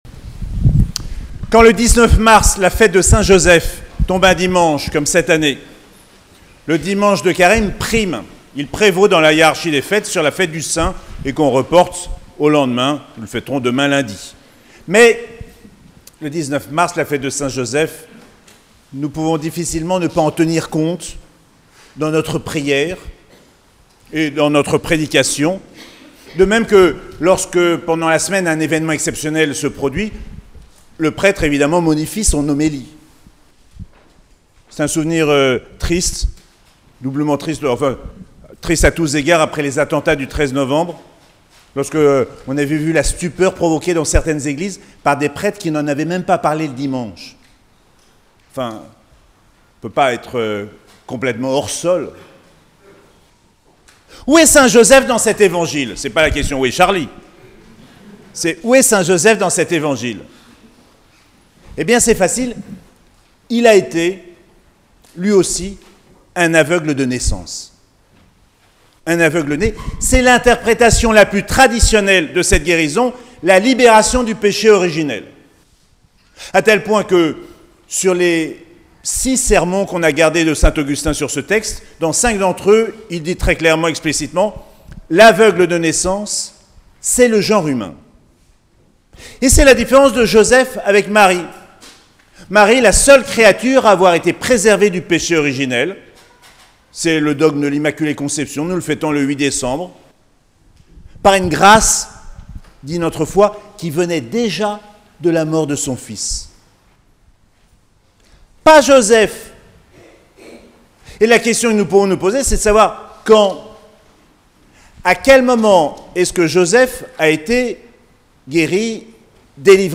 4ème dimanche de Carême - 19 mars 2023